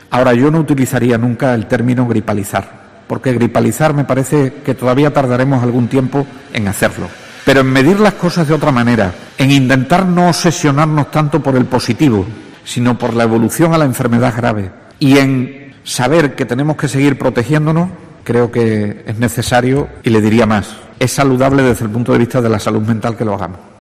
De esta forma se ha pronunciado Vergeles, a preguntas de los periodistas en rueda de prensa este lunes en Mérida, sobre la propuesta de la patronal de reducir las cuarentenas por Covid a cuatro días